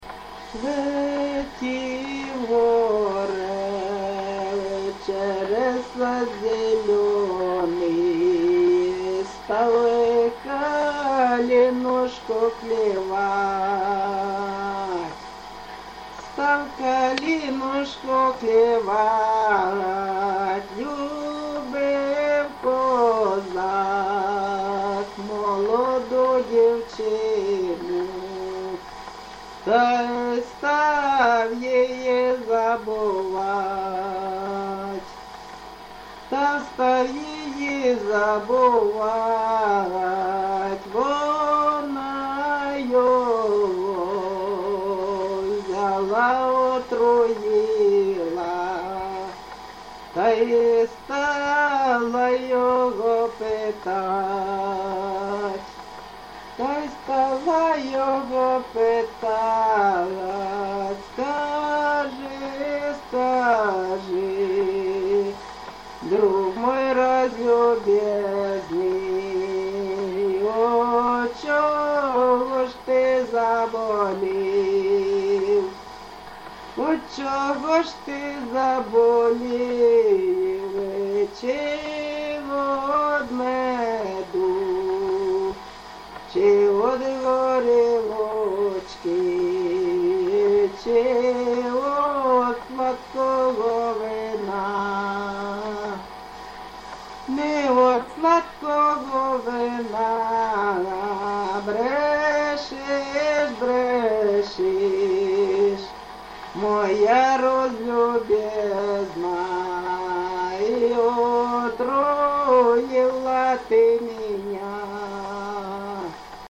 ЖанрПісні з особистого та родинного життя, Балади
Місце записус. Олександро-Калинове, Костянтинівський (Краматорський) район, Донецька обл., Україна, Слобожанщина